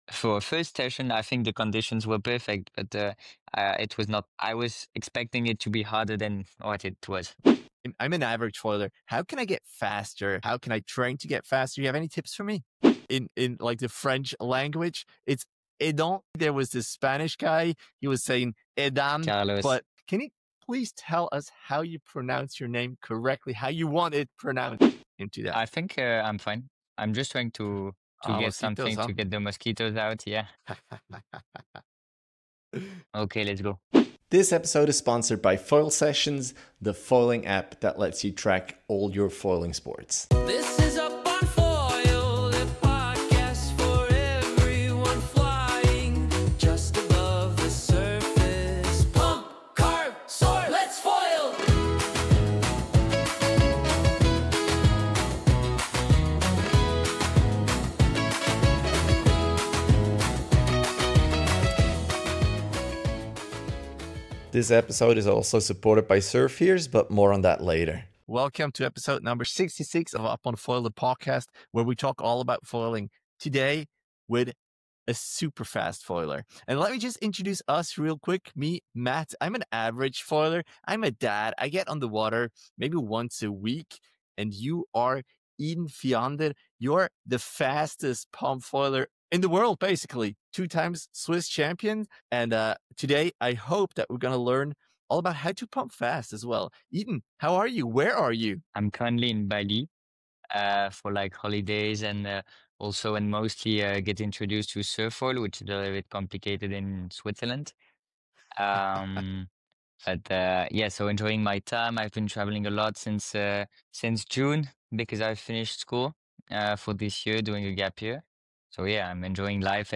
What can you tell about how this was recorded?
tells us his story while fighting mosquitoes on a warm evening in Bali